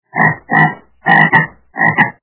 » Звуки » Природа животные » Жаба - Скрекочет
При прослушивании Жаба - Скрекочет качество понижено и присутствуют гудки.
Звук Жаба - Скрекочет